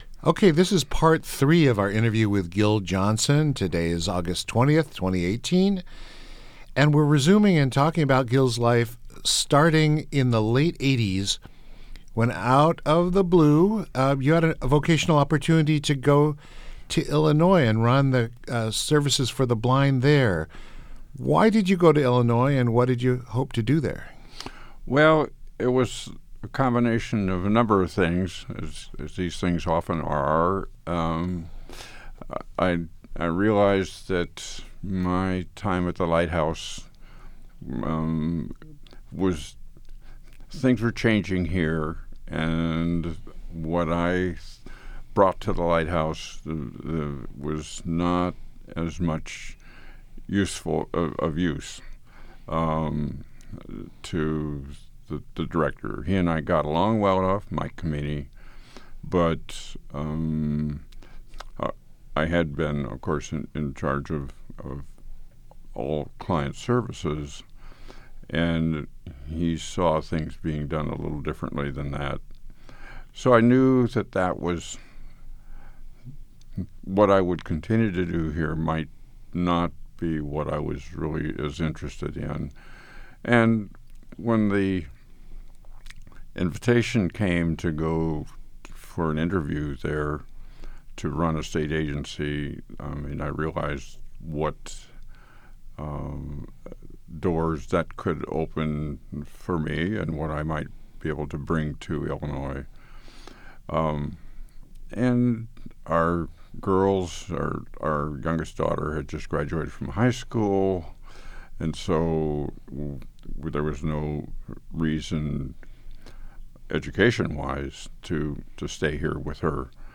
The podcast series was recorded on three separate days and is broken into seven total parts below.